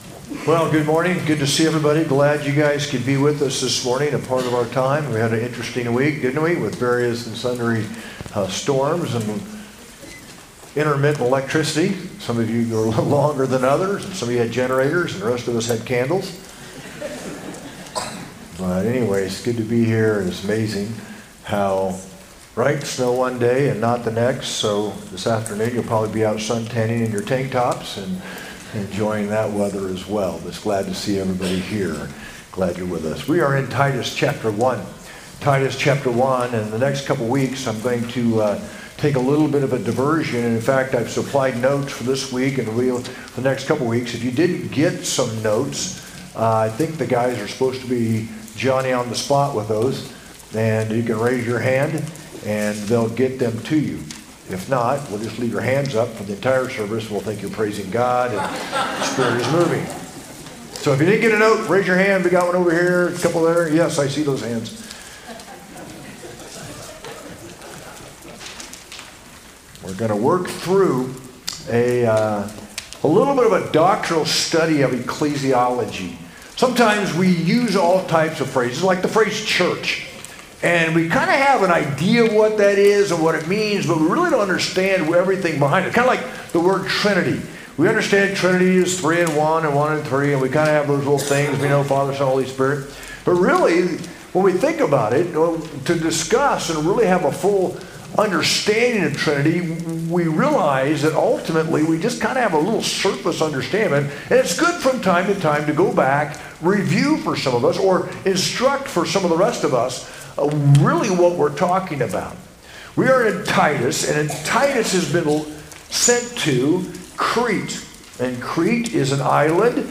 sermon-3-23-25.mp3